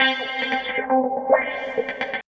136 GTR 3 -R.wav